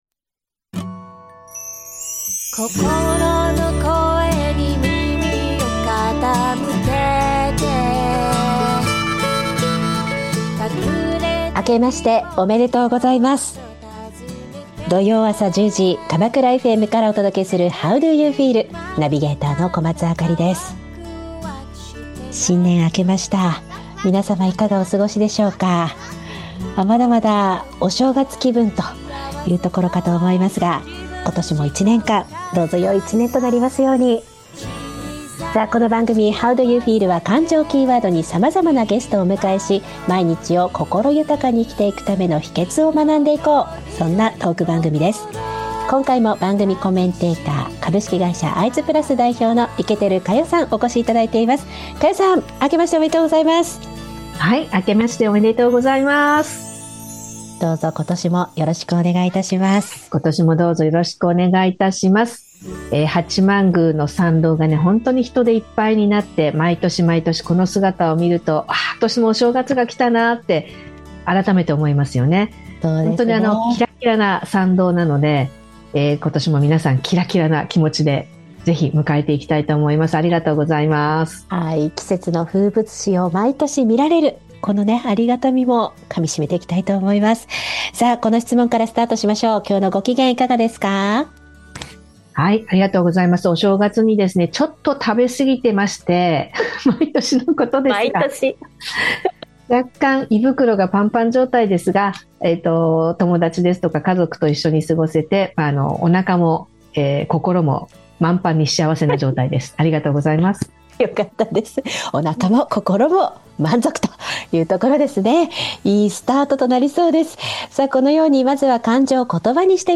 番組では「感情知性=EQ」に注目!自身の感情を見つめ、心を豊かにするヒントを学んでいく、ゲストトーク番組です。